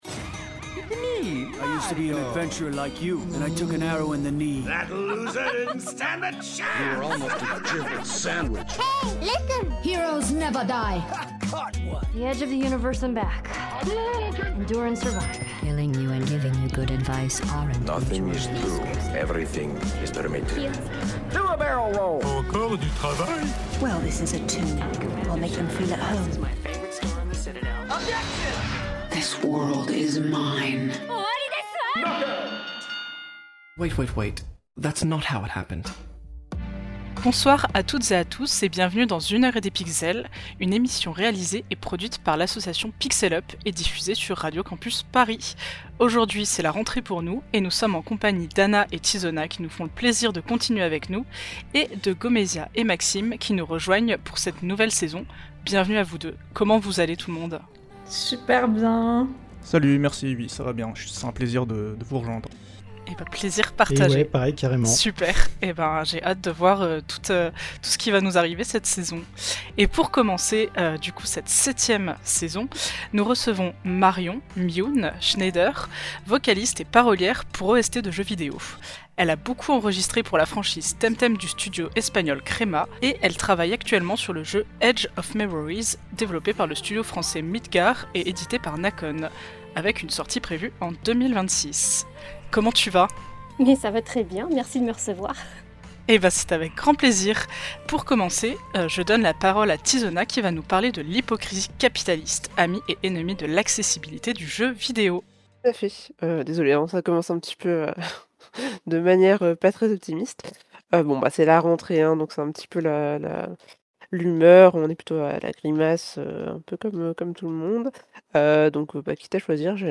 Émission diffusée le 4 octobre 2025 sur Radio Campus Paris.